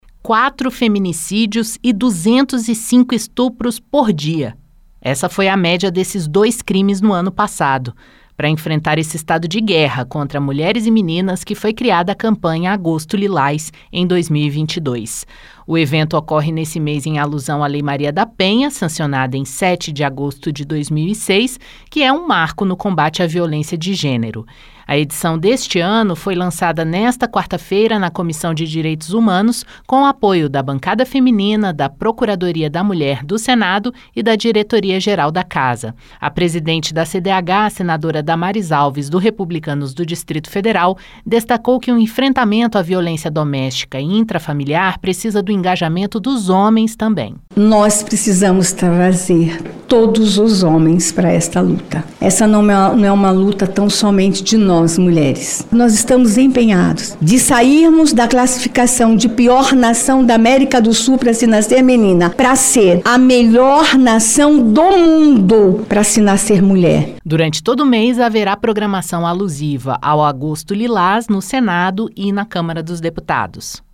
O evento lembra os 18 anos da Lei Maria da Penha e alerta para os altos índices de feminicídios e estupros no país. A senadora Damares Alves (Republicanos-DF) destacou a importância do engajamento dos homens na luta por um país mais seguro para mulheres e meninas.